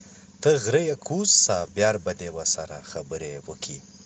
Ps-wanetsi-sentence_4.wav